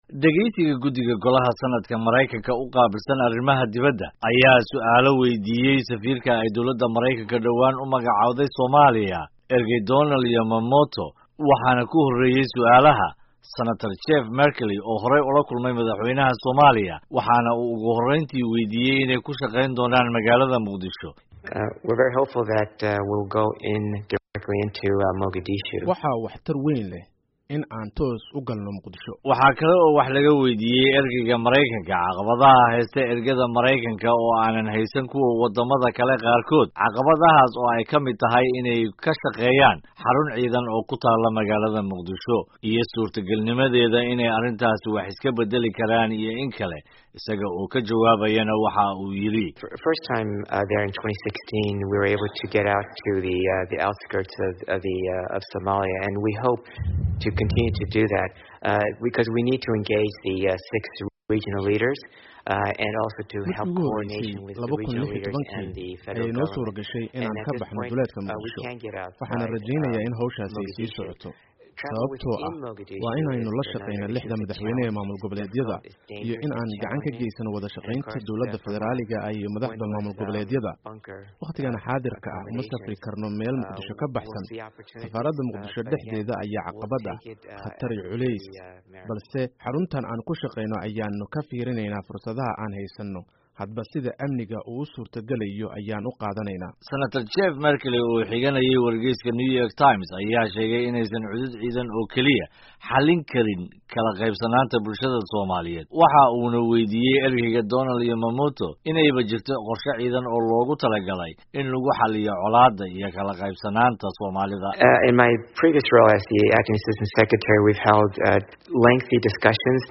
Warbixin Safiirka Mareykanka ee Somaliya oo ka hadlay arrimaha Somalia